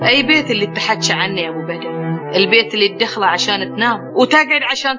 7_khaliji_drama.mp3